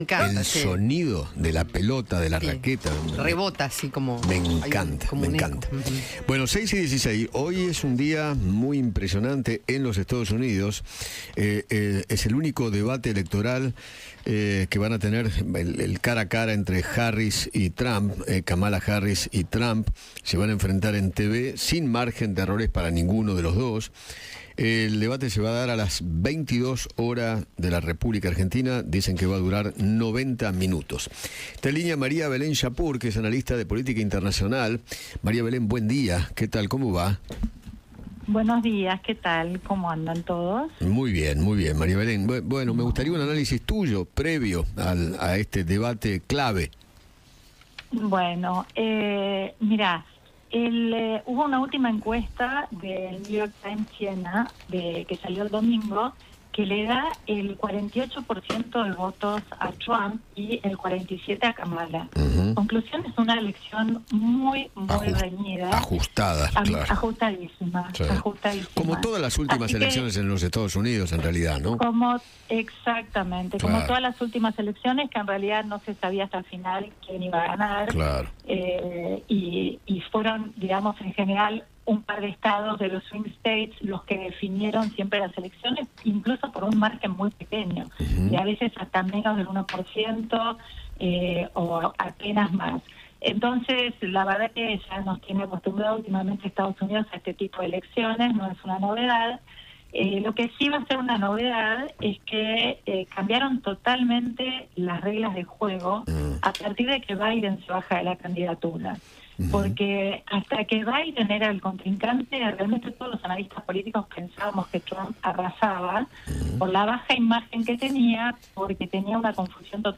analista internacional, dialogó con Eduardo Feinmann sobre el primer debate presidencial de los Estados Unidos, que estará protagonizado por Kamala Harris y Donald Trump.